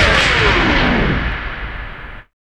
4607R SCRACH.wav